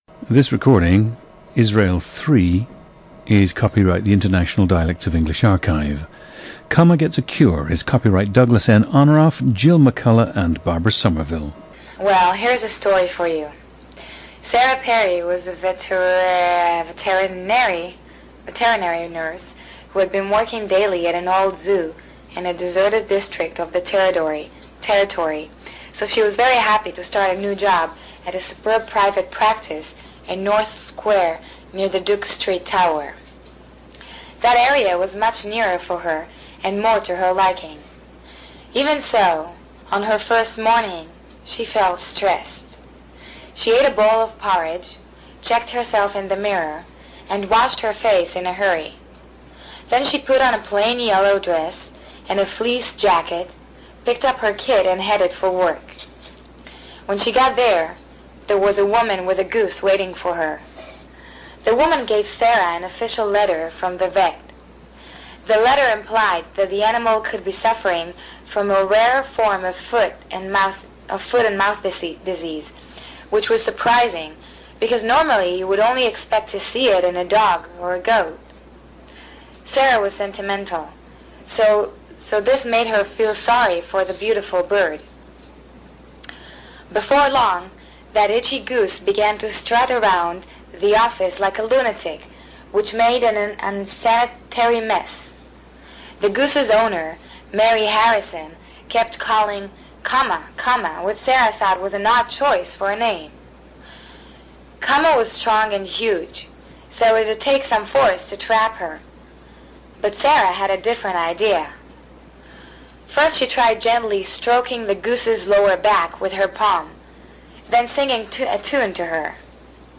PLACE OF BIRTH: Jerusalem, Israel
GENDER: female
The subject speaks Hebrew, Italian, French, and English.
The recordings average four minutes in length and feature both the reading of one of two standard passages, and some unscripted speech.